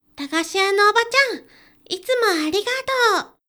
ボイス
ダウンロード 男性_「駄菓子屋のおばちゃん、いつもありがとう！」
キュート男性
dansei_dagashiyanoobachanitumoarigatou.mp3